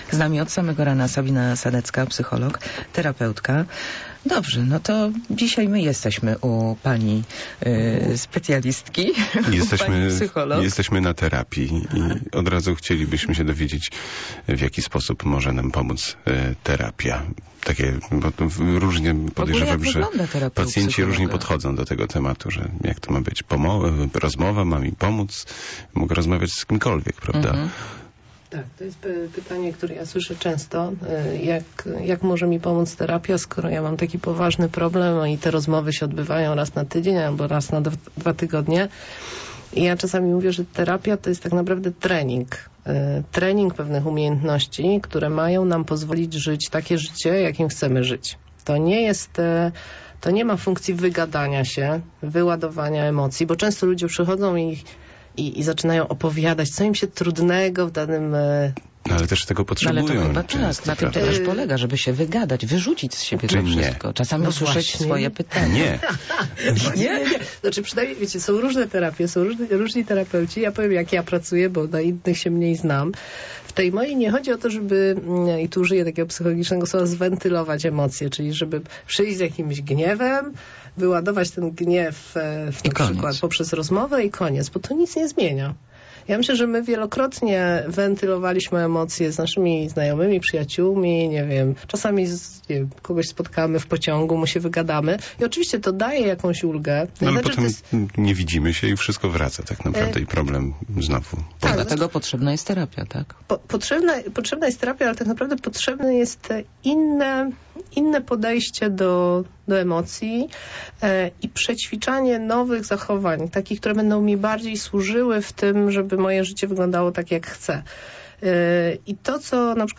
Gościem programu "Mała Czarna" była psycholog i terapeutka